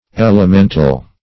Elemental \El`e*men"tal\ ([e^]l`[-e]*m[e^]n"tal), a.